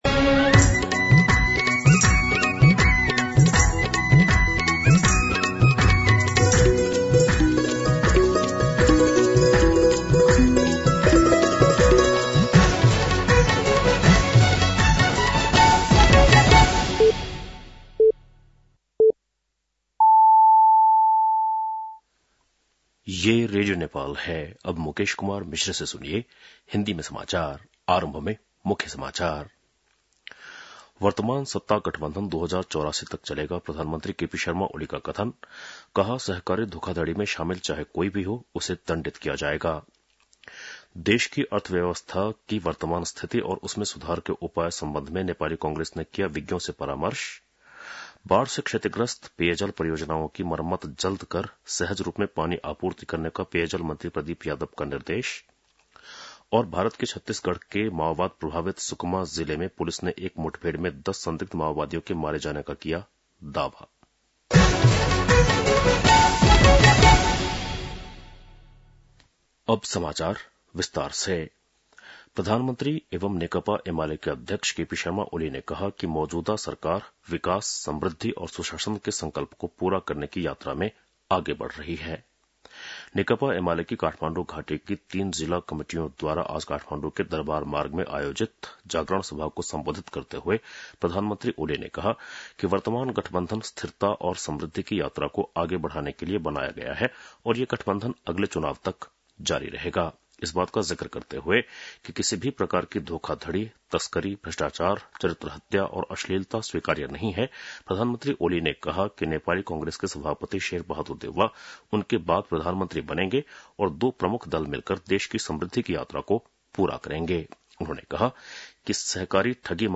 बेलुकी १० बजेको हिन्दी समाचार : ८ मंसिर , २०८१
10-PM-Hindi-News-8-7.mp3